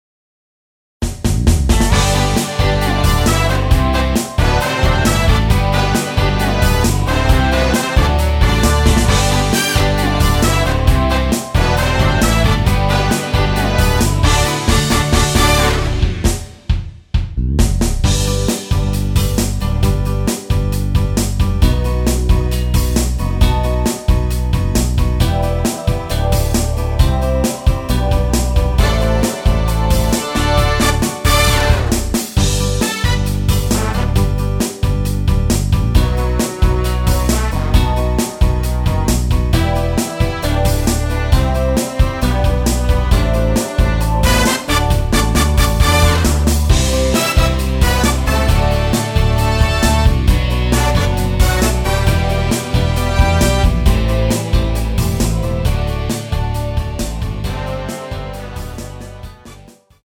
남성분이 부르실수 있는 키로 제작 하였습니다.(미리듣기 참조)
앞부분30초, 뒷부분30초씩 편집해서 올려 드리고 있습니다.
중간에 음이 끈어지고 다시 나오는 이유는